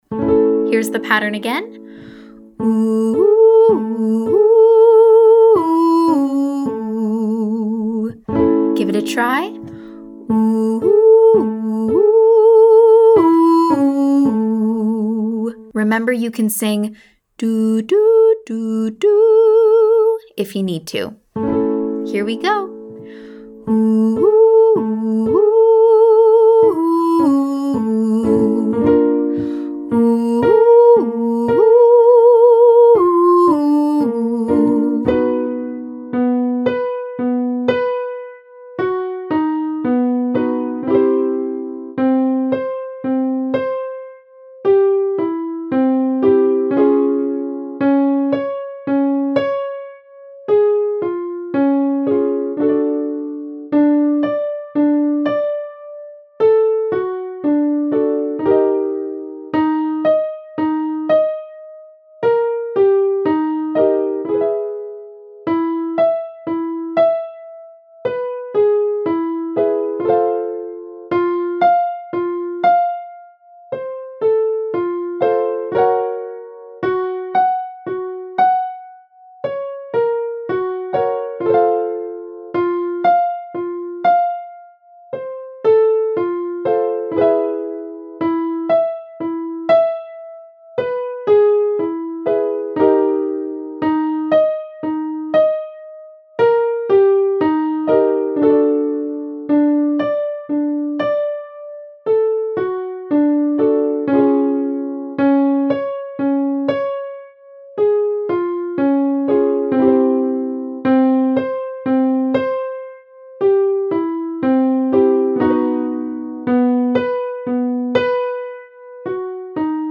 Notice we’re sustaining the second time we reach the octave, and if you can relax to let your natural vibrato come in, do so!
Vocal Agility Lesson 5B